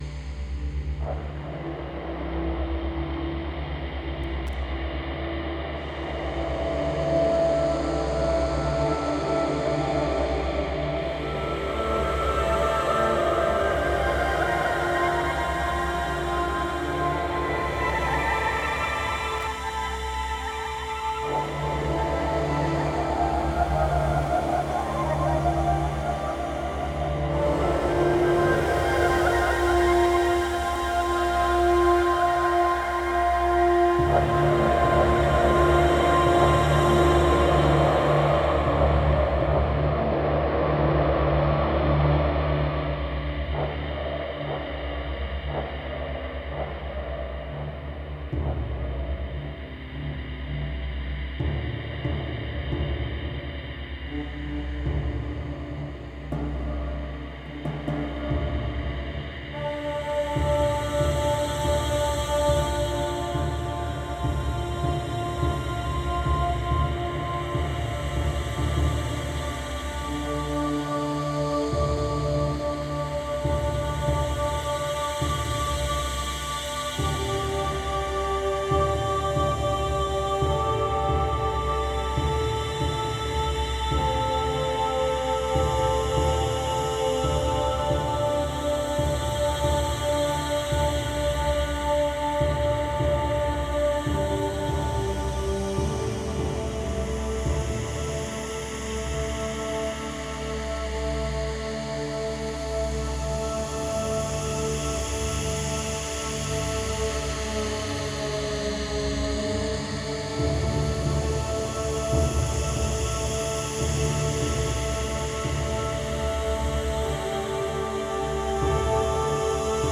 Spherical Soundtrack with Synths.